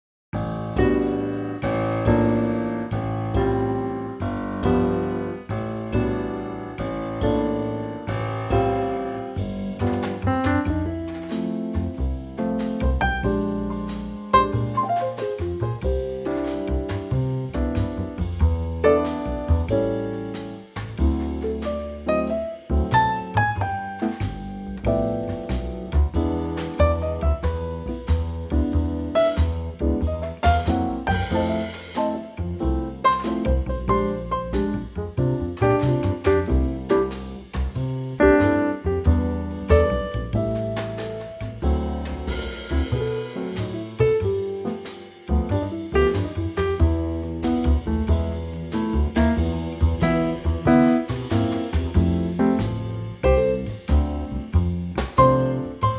Unique Backing Tracks
key C
PIANO SOLO REMOVED!
key - C - vocal range - Ab to C